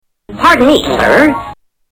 Tags: Television Cartoons Looney Toones Daffy Duck Daffy Duck Audio clips